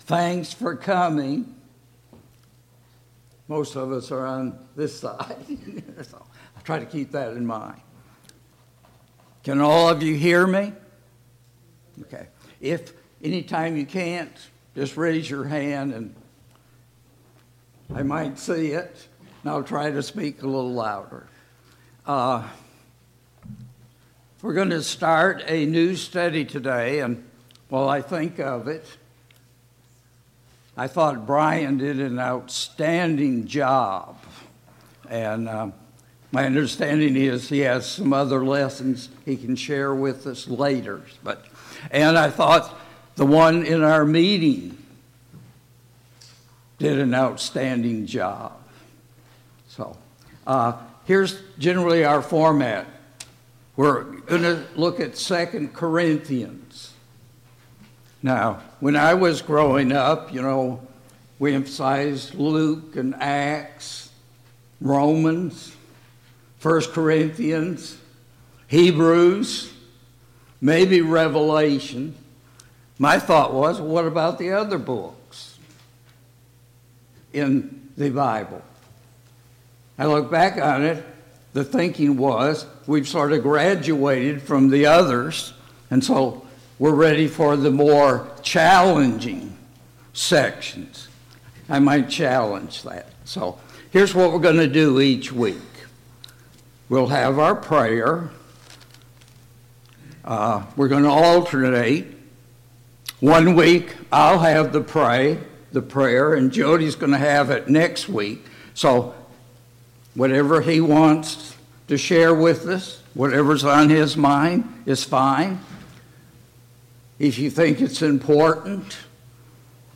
2 Corinthians 1 Service Type: Sunday Morning Bible Class « 10.